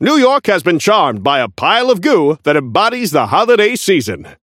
Newscaster voice line - New York has been charmed by a pile of goo that embodies the holiday season!
Newscaster_seasonal_viscous_unlock_01.mp3